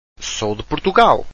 sou-de-portugal.wav